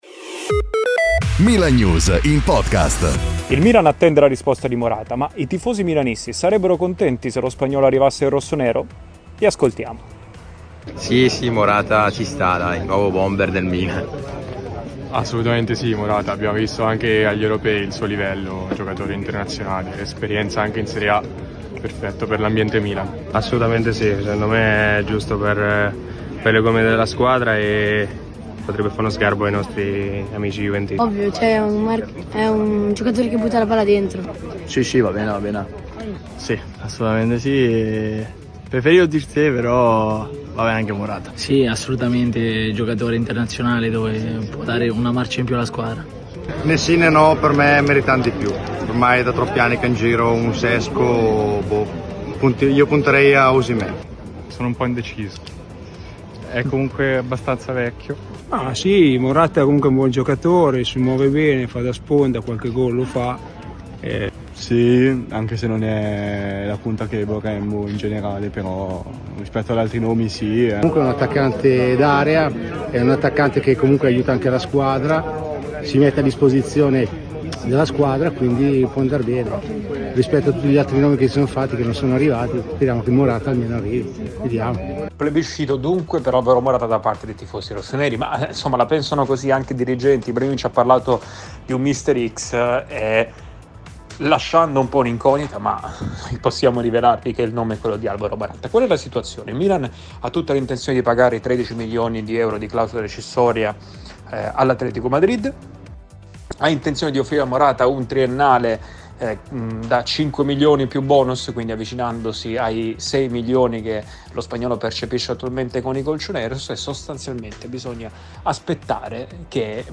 Plebiscito per Alvaro Morata tra i tifosi del Milan. Nel giorno del raduno della prima squadra, gli inviati di Milannews hanno chiesto ad alcuni dei tifosi rossoneri presenti se gradissero lo spagnolo come nuovo numero 9 del Milan.